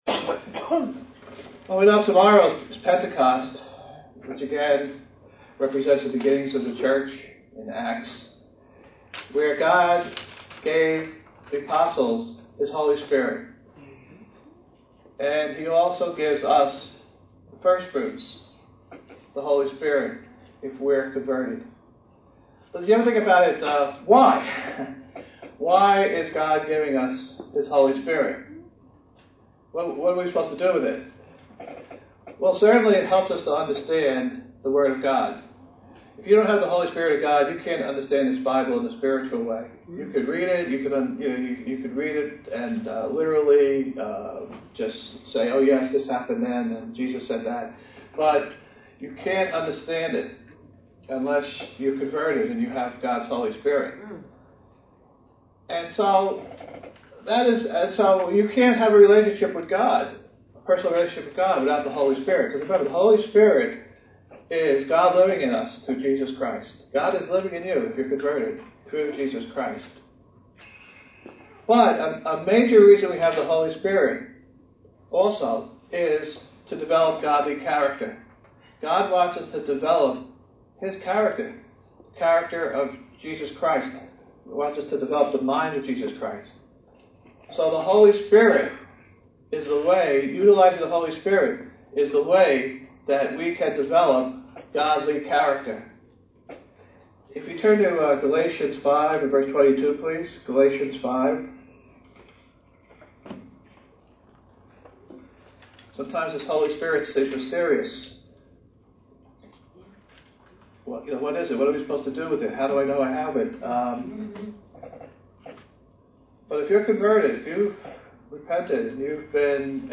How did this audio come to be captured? Given in New York City, NY